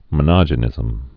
(mə-nŏjə-nĭzəm)